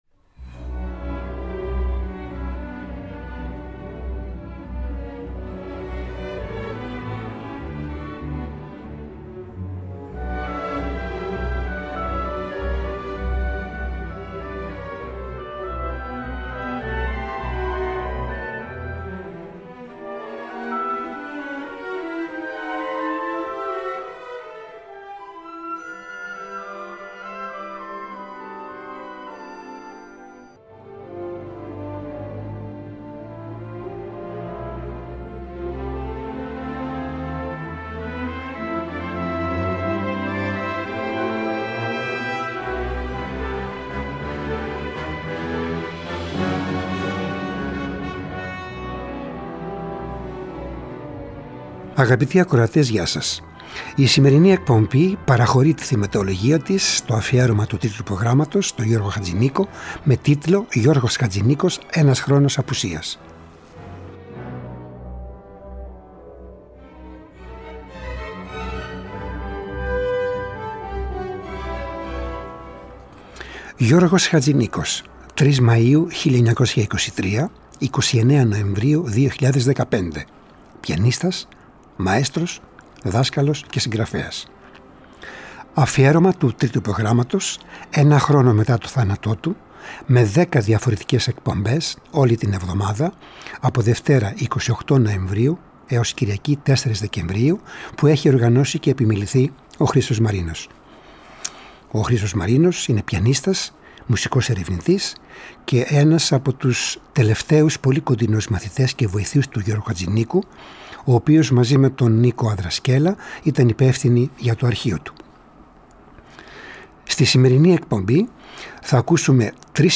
Θα ακούσουμε τον Γιώργο Χατζηνίκο ως πιανίστα, ως μαέστρο και στον διπλό ρόλο όπου παίζει διευθύνοντας από το πιάνο.